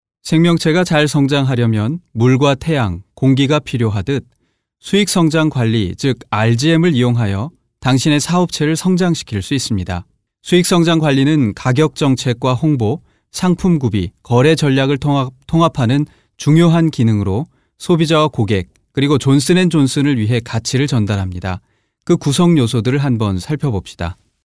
轻松自然 小语种配音